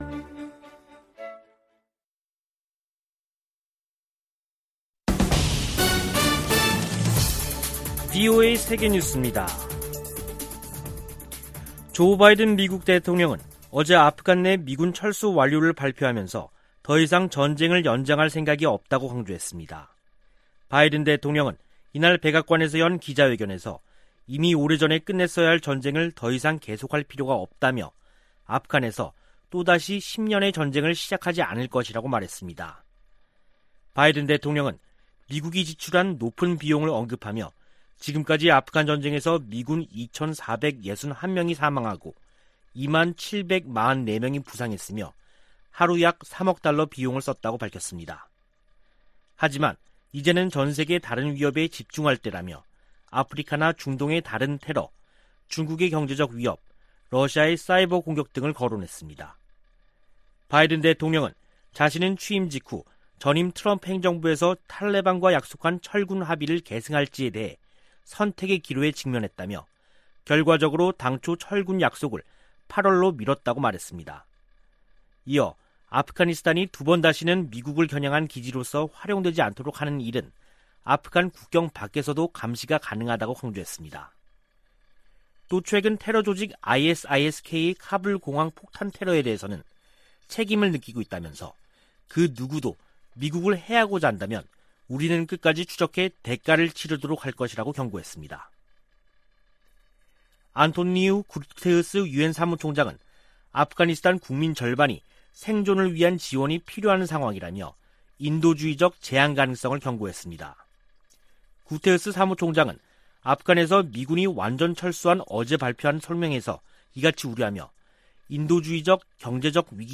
세계 뉴스와 함께 미국의 모든 것을 소개하는 '생방송 여기는 워싱턴입니다', 저녁 방송입니다. '지구촌 오늘'에서는 아프가니스탄 철군이 옳은 결정이었다고 조 바이든 미국 대통령이 강조한 소식, '아메리카 나우'에서는 텍사스주에서 임신 6주 이후 중절을 금지하는 이야기 전해드립니다.